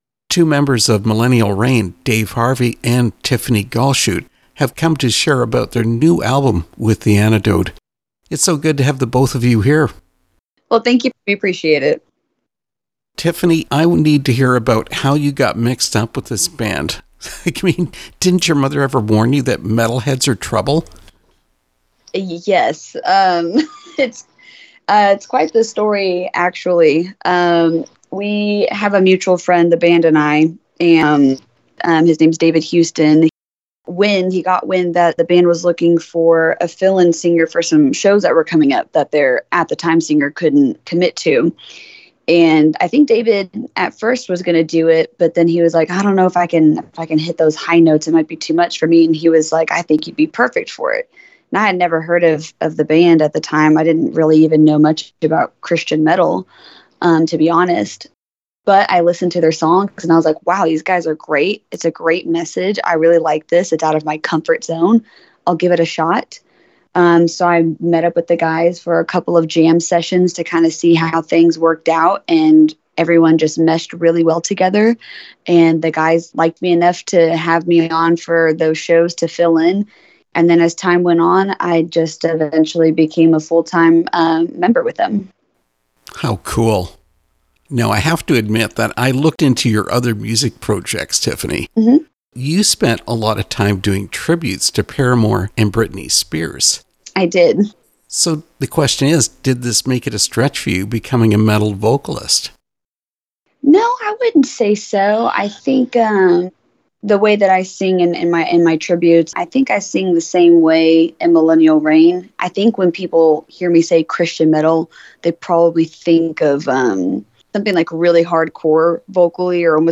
Interview with Millennial Reign – 2024
millennial-reign-2024-interview.mp3